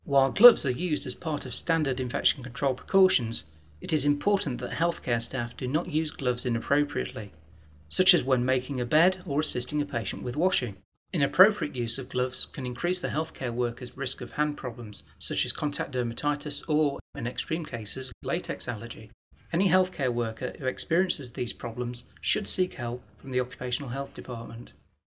Learning Objects : Lifelong Application Glove Use Narration audio (MP4) Narration audio (OGG) Contents Why use gloves?